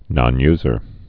(nŏn-yzər)